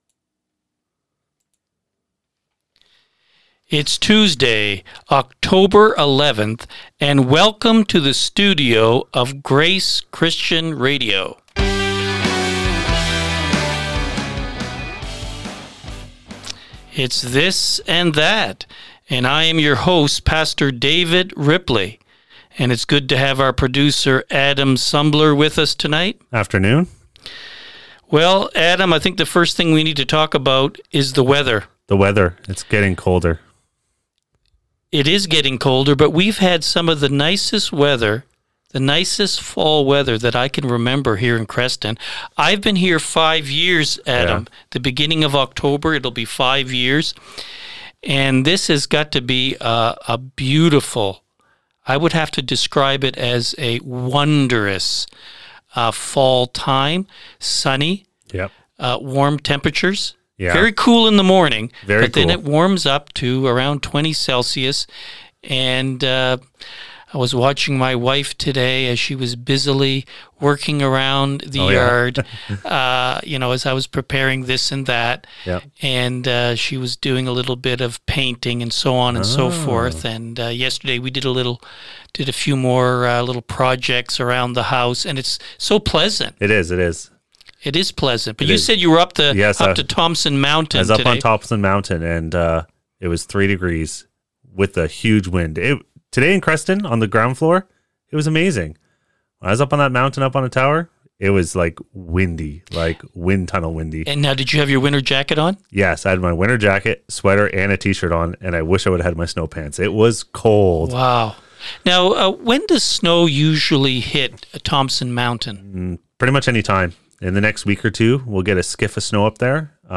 "This & That" Live Commentary